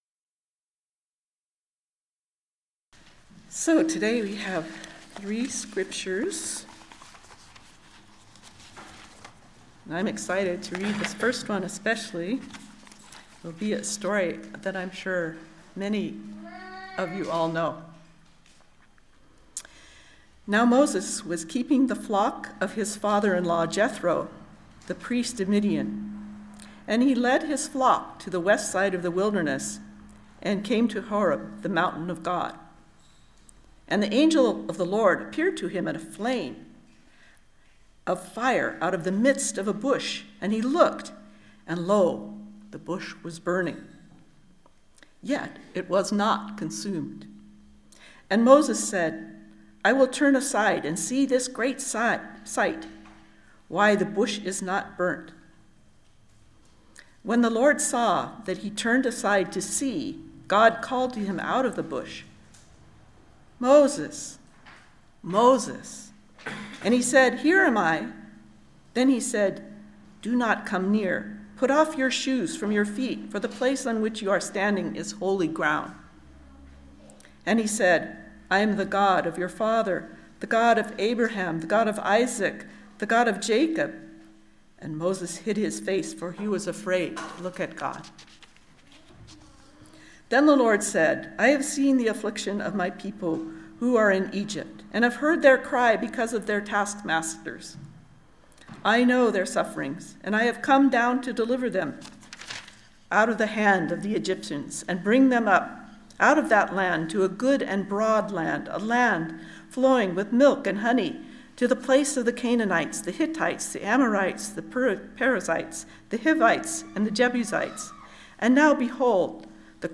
Listen to the most recent message from Sunday worship, “Repent, and Bear Fruit,” at Berkeley Friends Church.